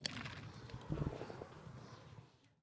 Minecraft Version Minecraft Version snapshot Latest Release | Latest Snapshot snapshot / assets / minecraft / sounds / block / trial_spawner / ambient5.ogg Compare With Compare With Latest Release | Latest Snapshot
ambient5.ogg